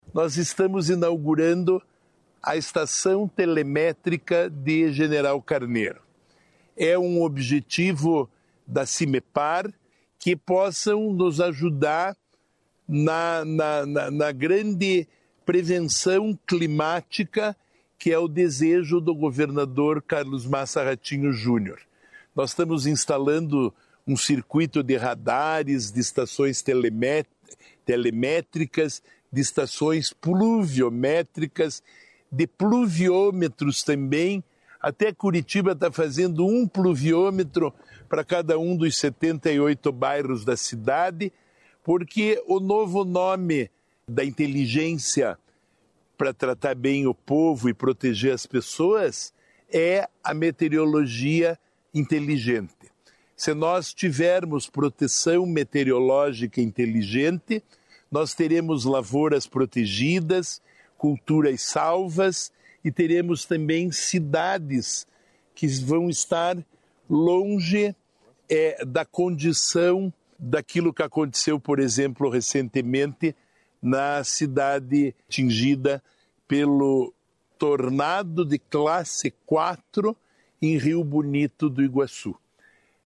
Sonora do secretário estadual de Desenvolvimento Sustentável, Rafael Greca, sobre nova estação meteorológica em General Carneiro